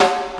• 1990s Ska Acoustic Snare F# Key 01.wav
Royality free snare drum tuned to the F# note. Loudest frequency: 1254Hz